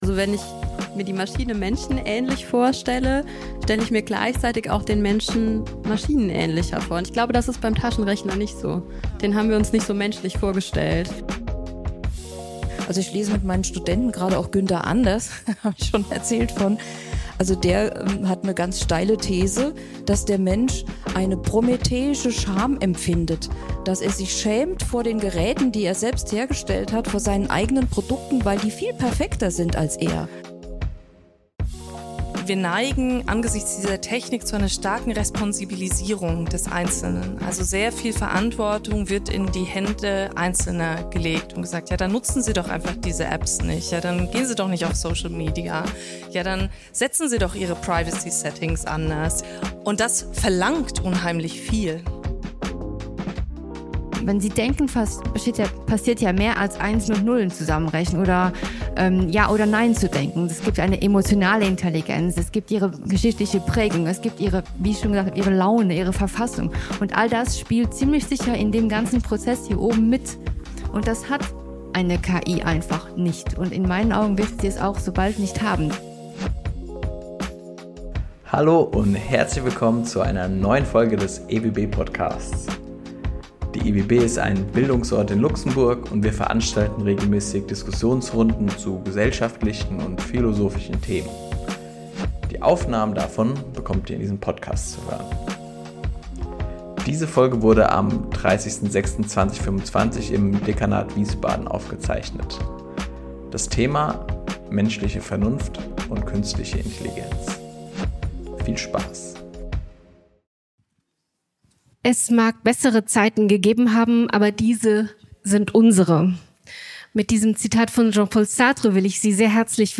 Wie verändern sich menschliche Freiheit und Selbstbestimmung in Zeiten Künstlicher Intelligenz? Es diskutieren: